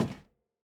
Flats_Metal_Grate_003.wav